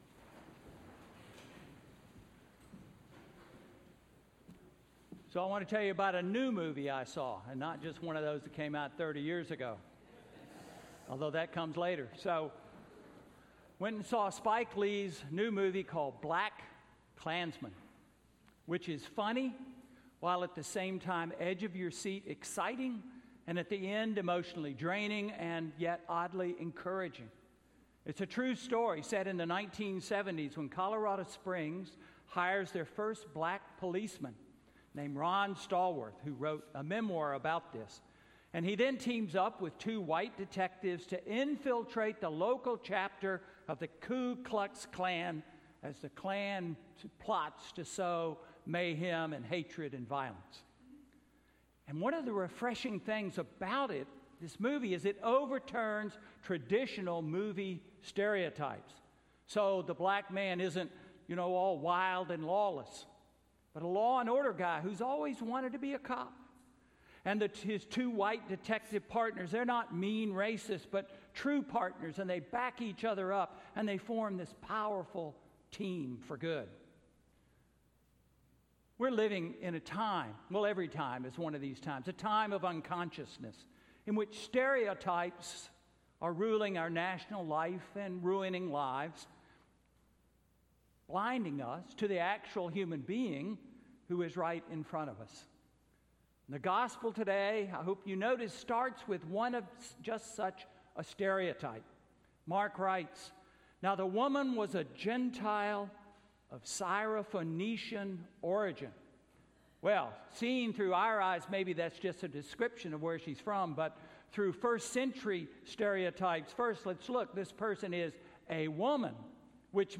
Sermon–Stereotypes or Reality–September 9, 2018